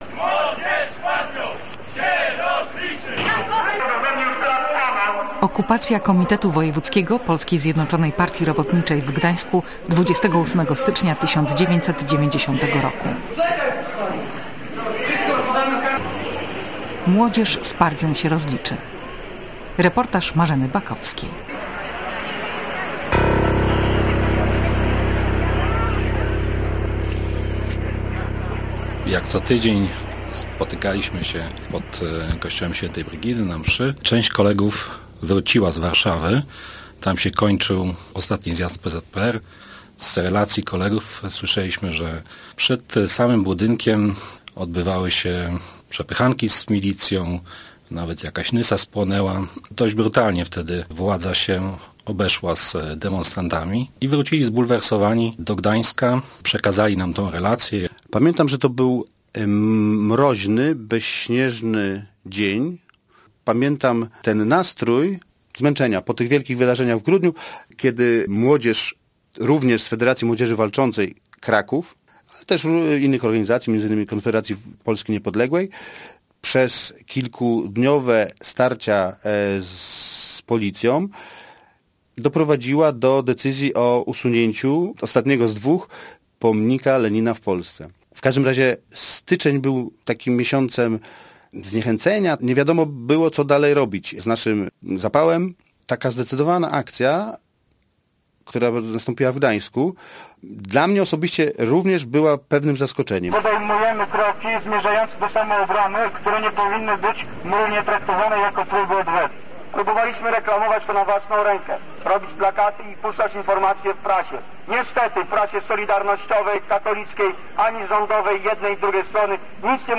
Młodzież z partią się rozliczy. Reportaż w 30. rocznicę okupacji KW PZPR w Gdańsku